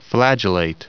Prononciation du mot flagellate en anglais (fichier audio)
Prononciation du mot : flagellate